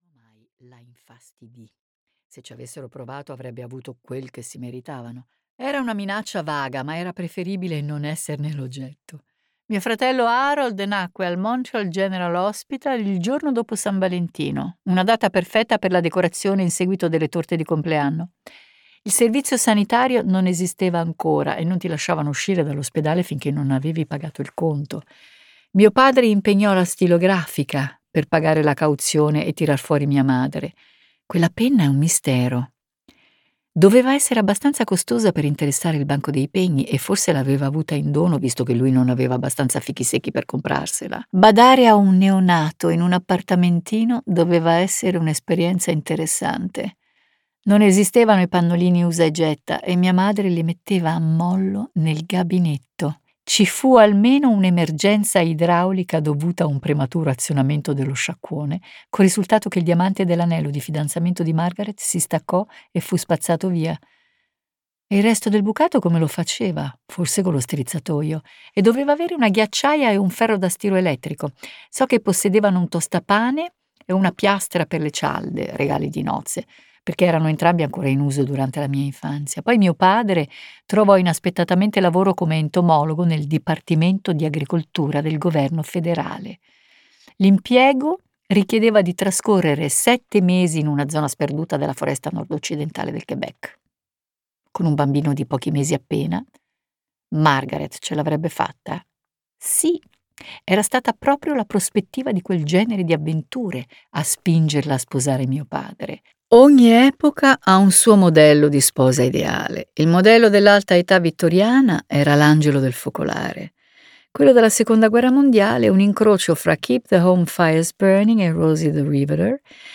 "Le nostre vite" di Margaret Atwood - Audiolibro digitale - AUDIOLIBRI LIQUIDI - Il Libraio